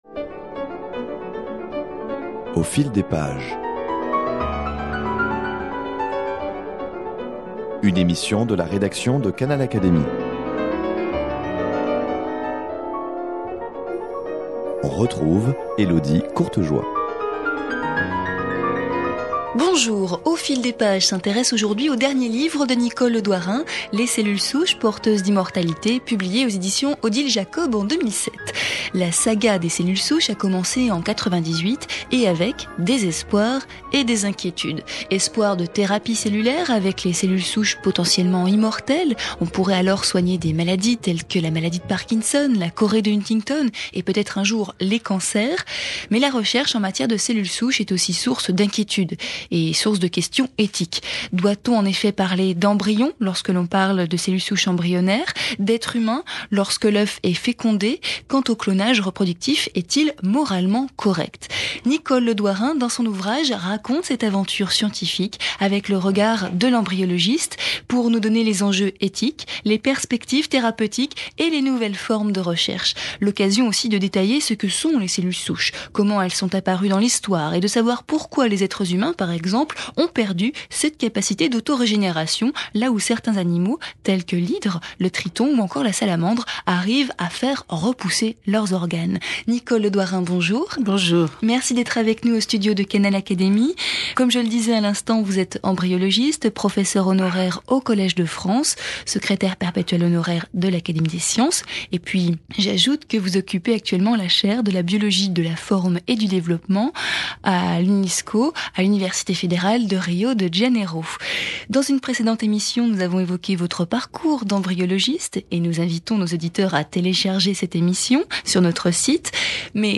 Au cours de cette émission, Nicole Le Douarin développe quelques thèmes de son ouvrage Les cellules souches porteuses d’immortalité.